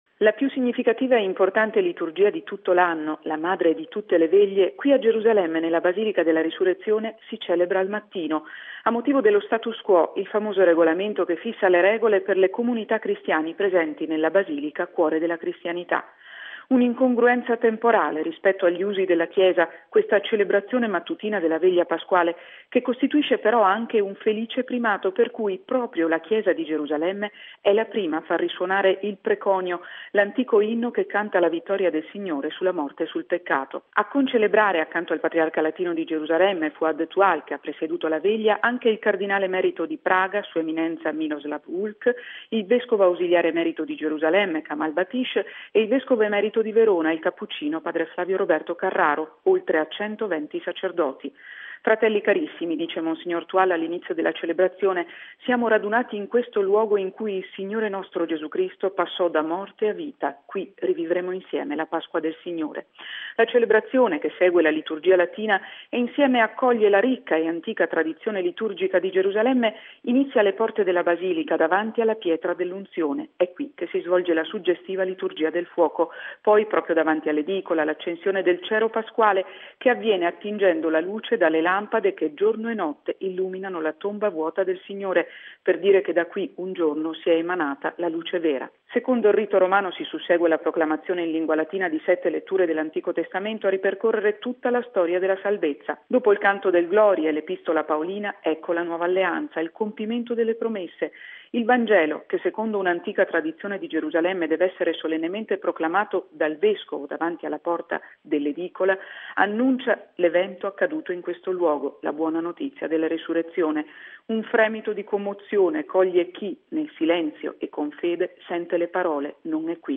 Da Gerusalemme, il servizio di